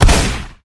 snd_shotgun.ogg